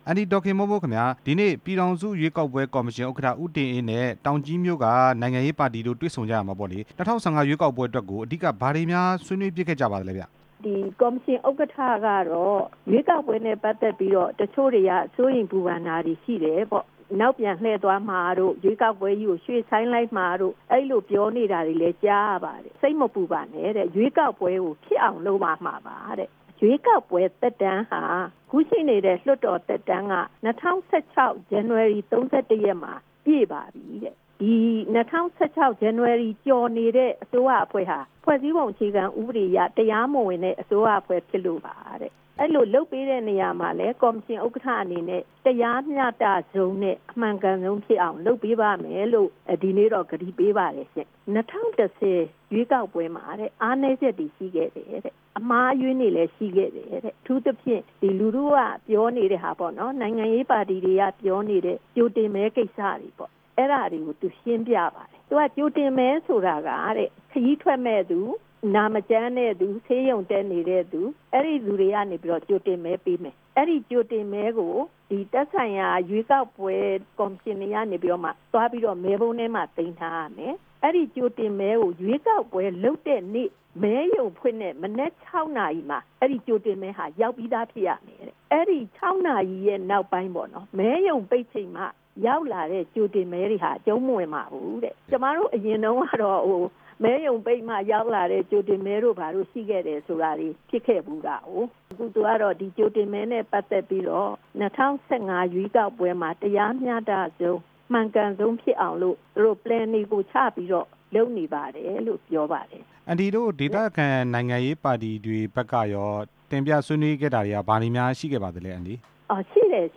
၂ဝ၁၅ အထွေထွေရွေးကောက် ပွဲကို မဖြစ်မနေကျင်းပမယ့် အကြောင်း မေးမြန်းချက်